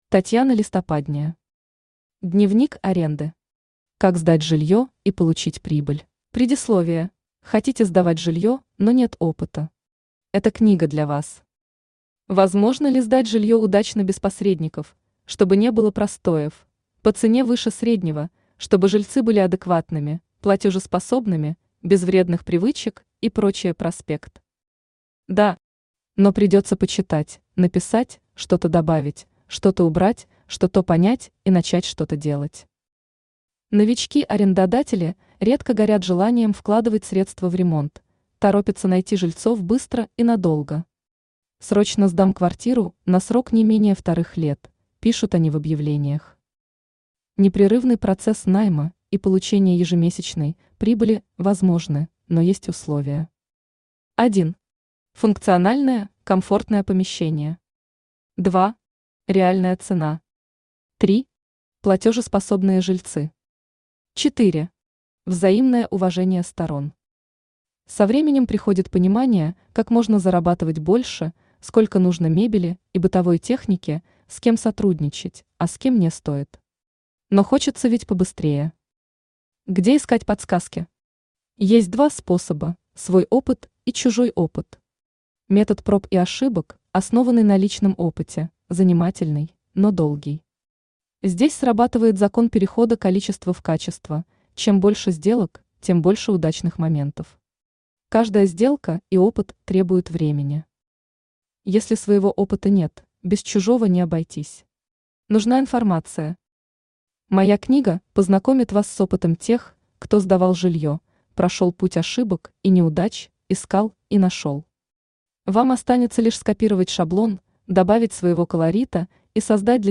Аудиокнига Дневник аренды. Как сдать жильё и получить прибыль | Библиотека аудиокниг
Как сдать жильё и получить прибыль Автор Татьяна Борисовна Листопадняя Читает аудиокнигу Авточтец ЛитРес.